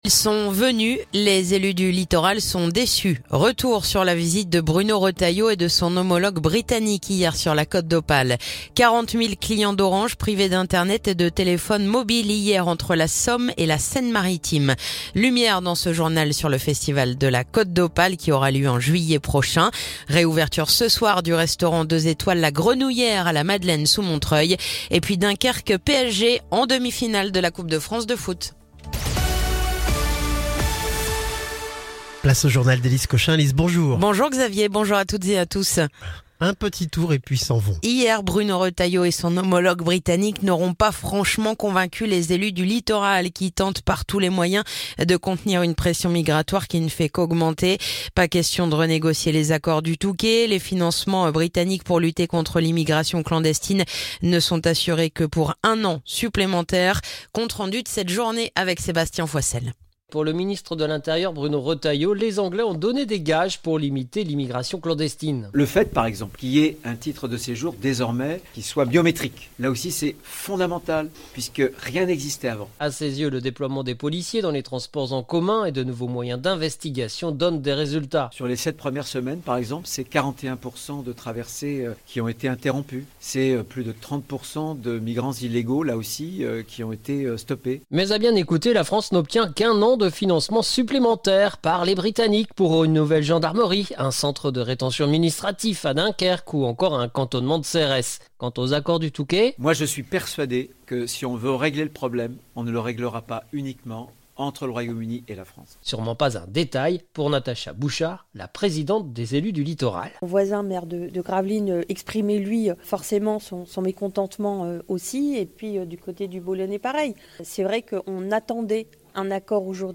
Le journal du vendredi 28 février